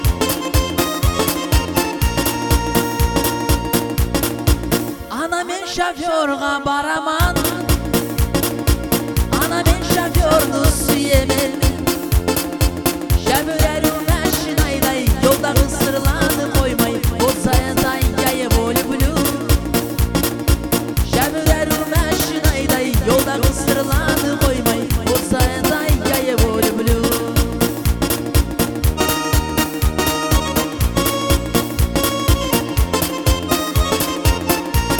Жанр: Поп музыка / Русский поп / Русские
Adult Contemporary, Pop